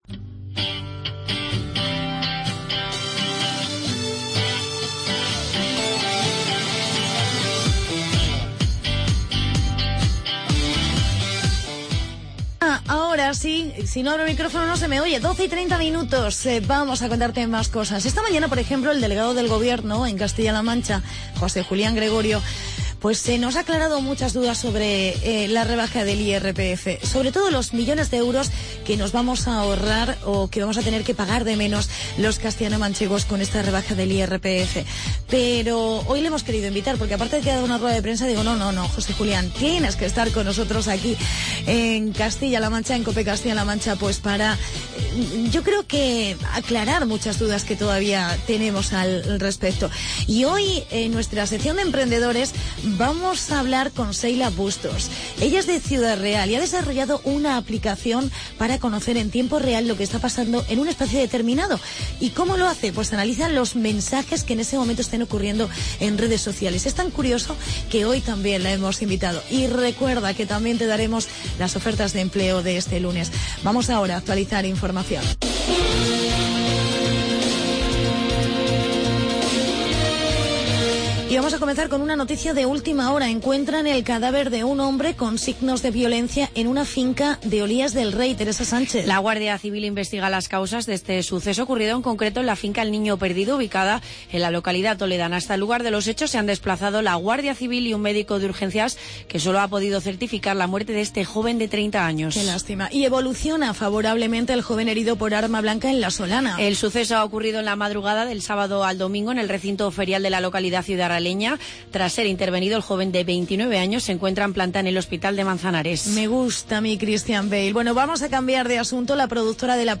Hablamos con el Delegado del Gobierno, José Julián Gregorio.
entrevista